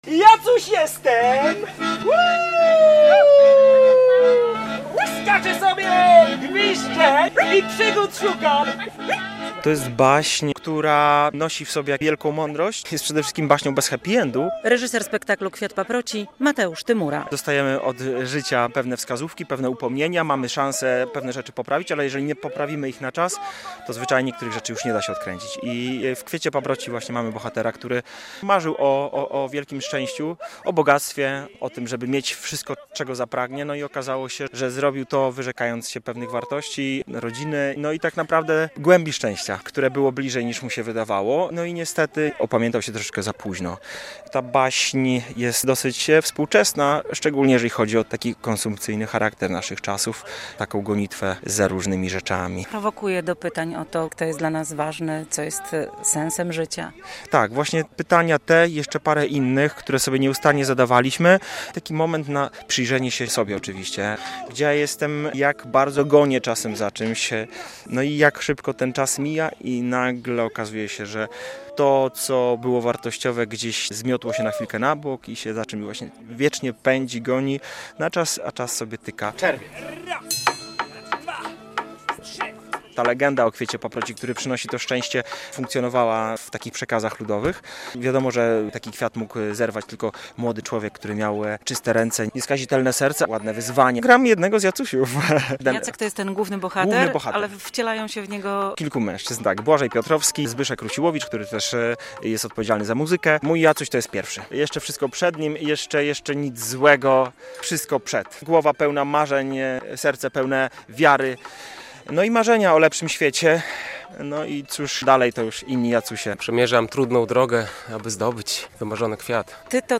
Widowisko powstało na podstawie pięknej baśni nawiązującej do polskiej tradycji Nocy Świętojańskiej. W piątkowy (26.08) wieczór aktorzy rywalizowali z komarami o uwagę widzów na bulwarach w Supraślu.